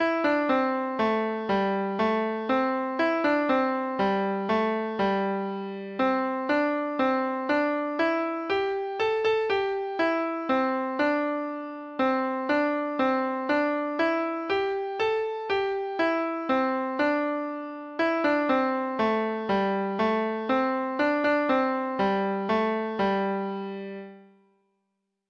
Traditional Trad. The Wagoner's Lad Treble Clef Instrument version
Folk Songs from 'Digital Tradition' Letter T The Wagoner's Lad
Traditional Music of unknown author.